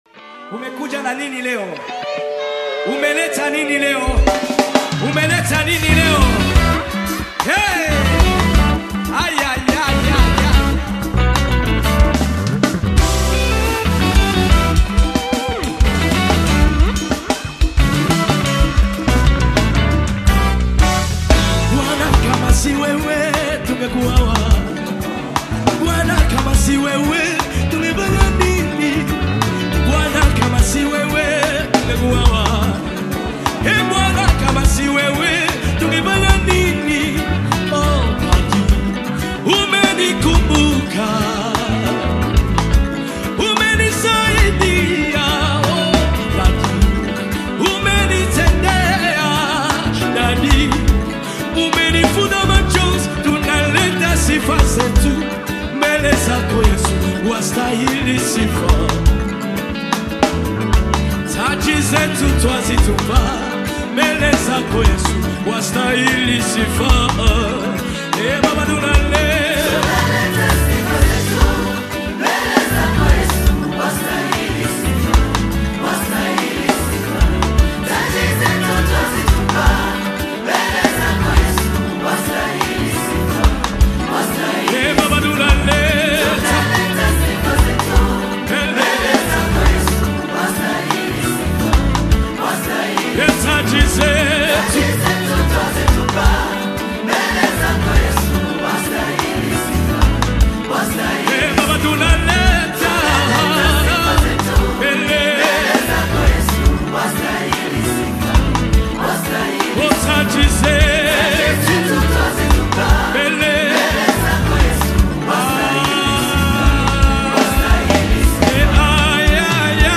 Download Gospel Song